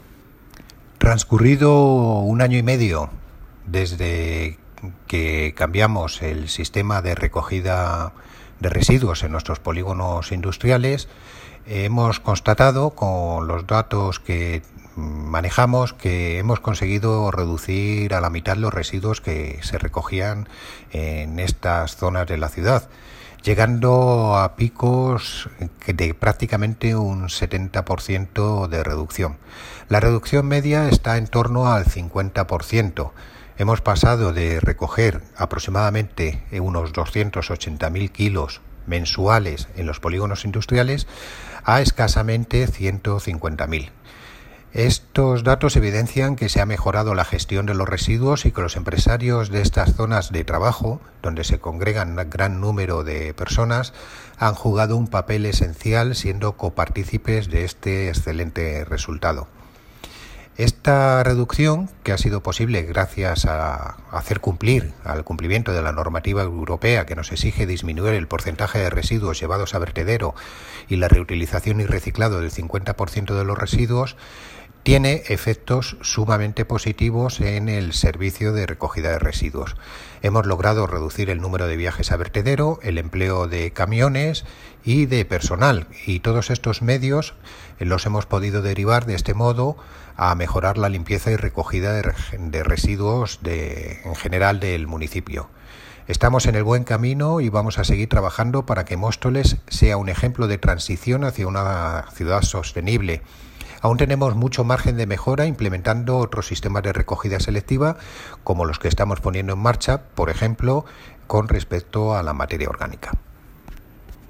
Audio - Miguel Ángel Ortega (Concejal de Medio Ambiente, Parques y Jardines y Limpieza Viaria)